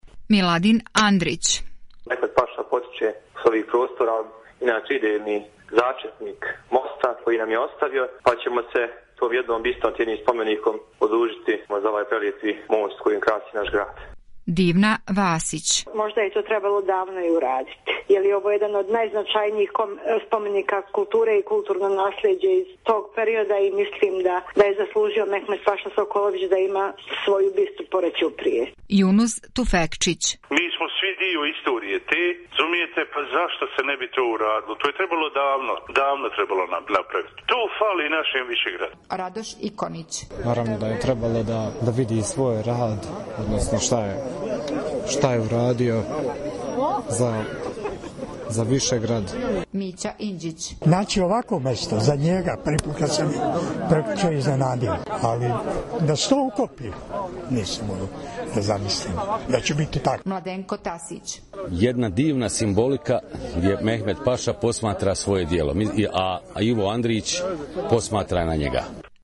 Anketa: Stavovi građana Višegrada